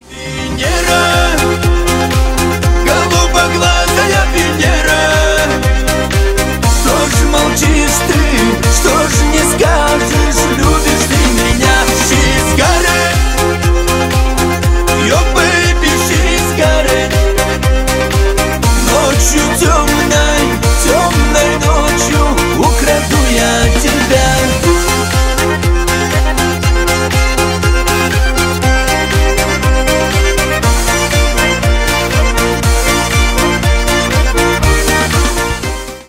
Шансон
кавказские